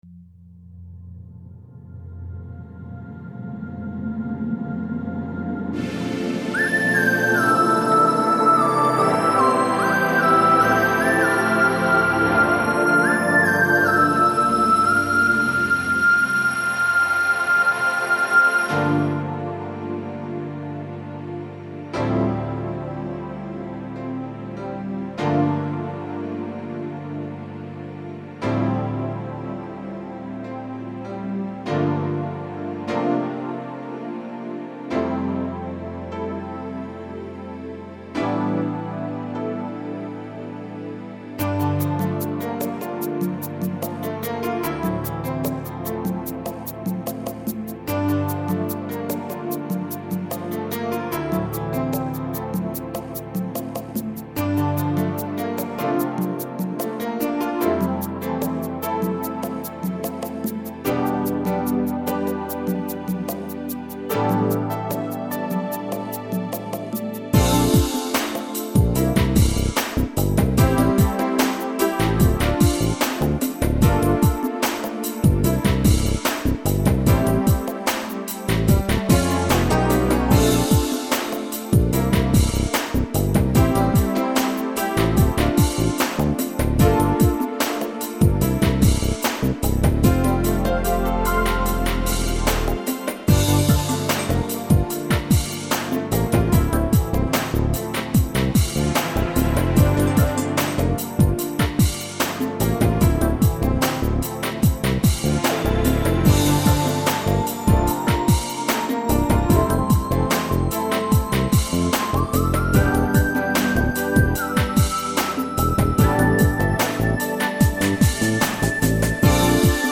готовый минус